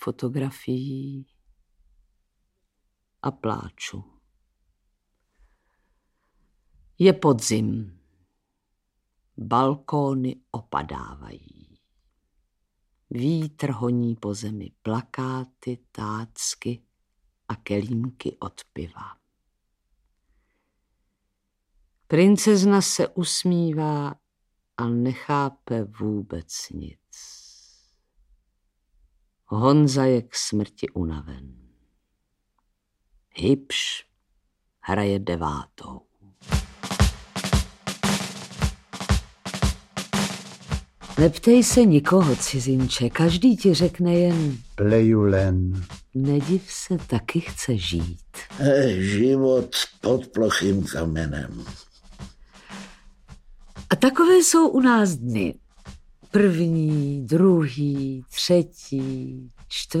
Audiobook
Read: Antonie Hegerlíková